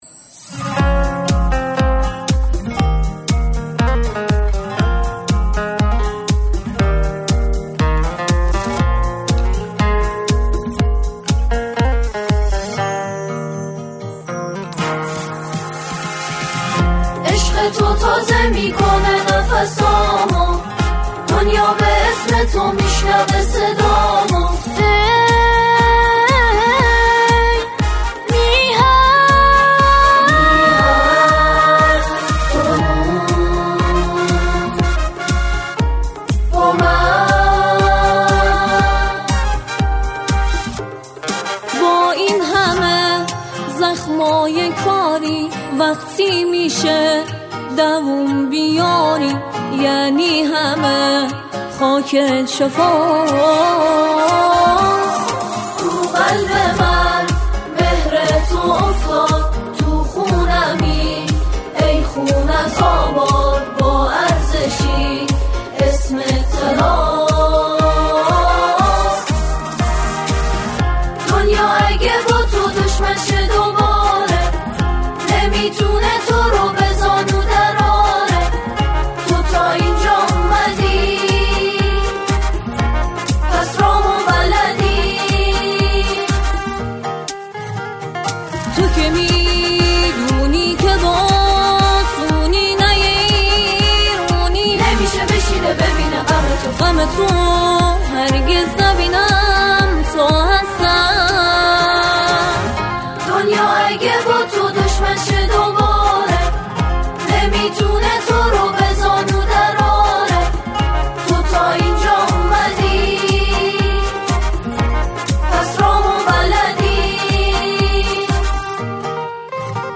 آهنگ این اثر توسط این گروه بازخوانی شده است.